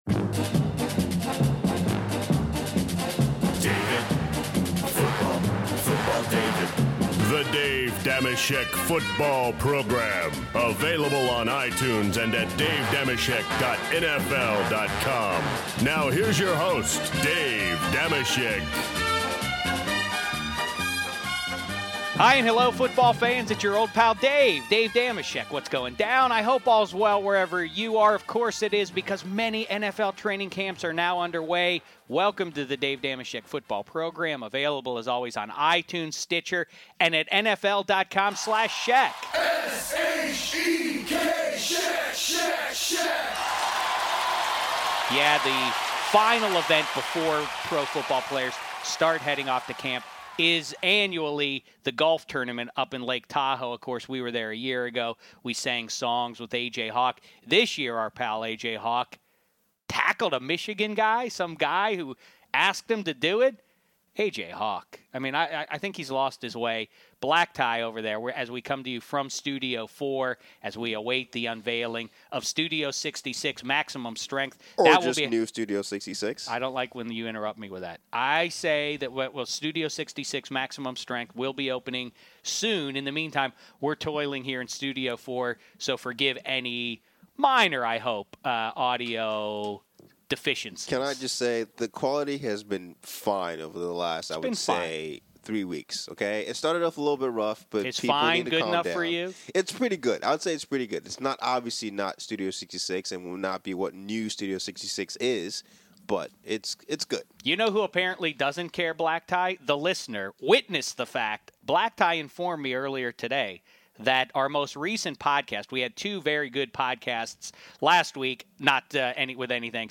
Then, Arizona Cardinals cornerback Antonio Cromartie calls in to discuss teammate Patrick Peterson vs. Darrelle Revis, Bruce Arians vs. Rex Ryan, Cardinals D vs. Seahawks D and Mark Sanchez vs. Tim Tebow.